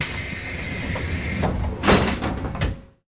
Звуки дверей поезда
Звук закрывающихся дверей поезда